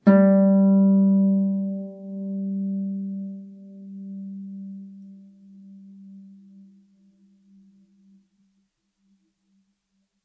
KSHarp_G3_mf.wav